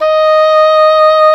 WND OBOE D#5.wav